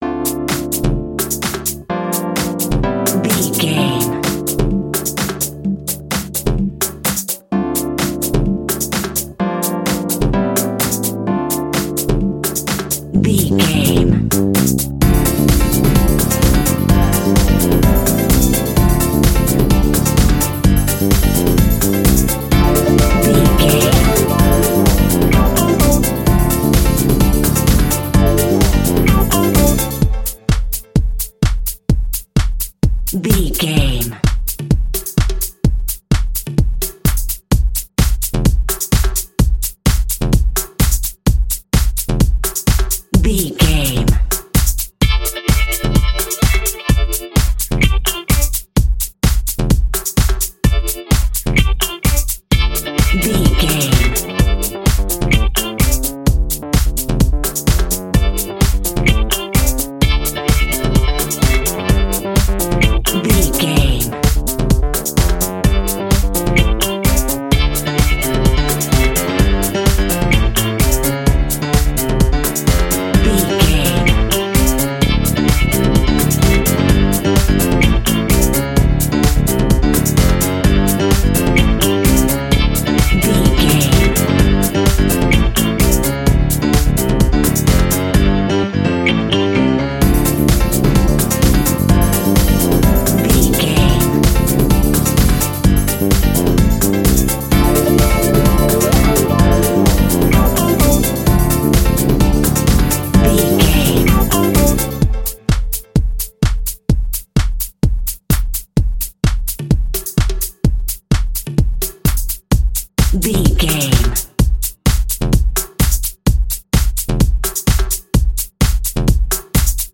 Glitch Dance House Music.
Aeolian/Minor
groovy
smooth
futuristic
uplifting
drum machine
synthesiser
bass guitar
electric guitar
piano
electro house
funky house